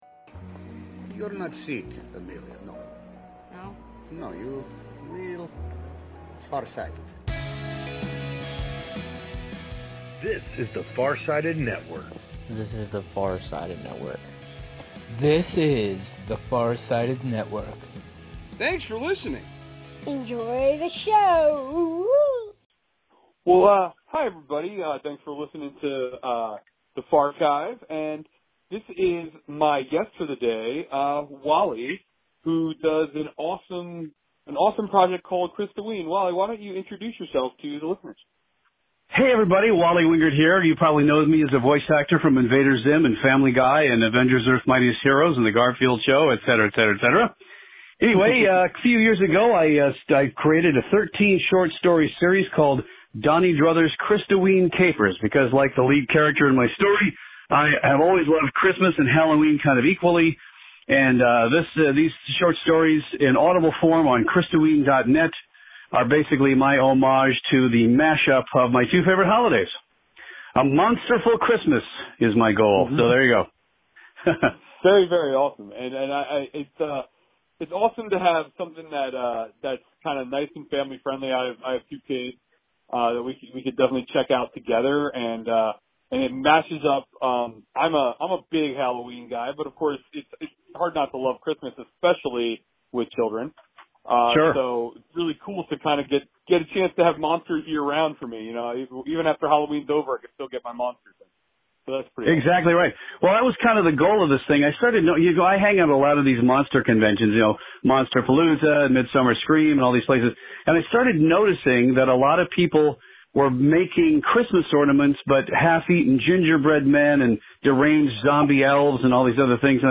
The rebranded pod features raw and (mostly) unedited interviews, both new and not-so-new.